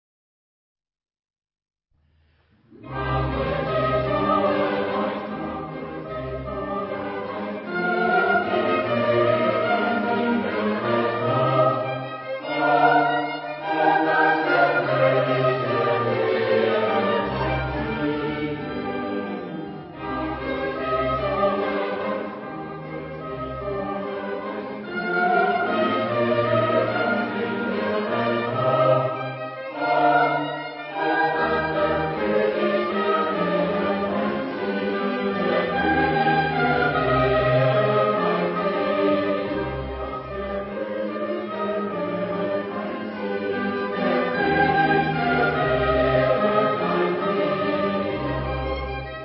Género/Estilo/Forma: Sagrado ; Cantata ; Barroco
Tipo de formación coral: SATB  (4 voces Coro mixto )
Solistas : STB  (3 solista(s) )
Tonalidad : mi bemol mayor